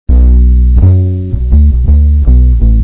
But in our examples we use samples with low quality because they are more fast in downloading.
As you can see, there are two beats in this sample.
bass.wav